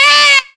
FALLING WAIL.wav